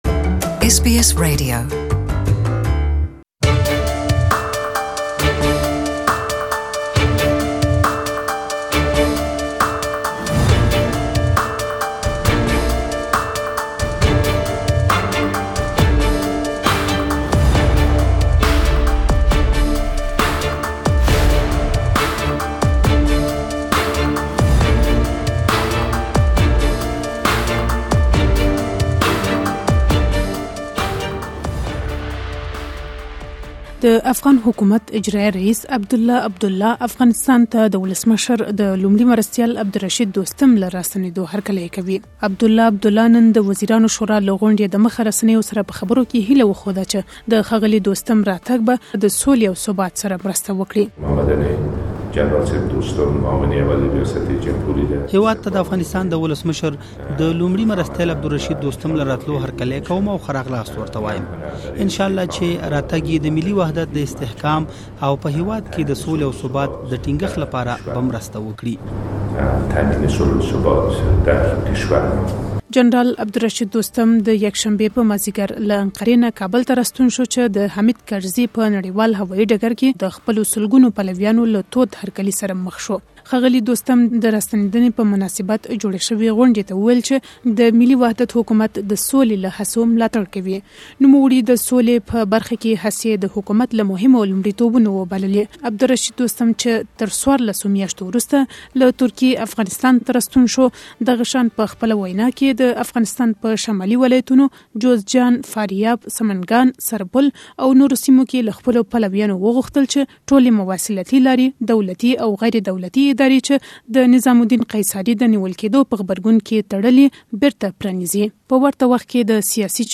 For more details, please listen to the full report in Pashto.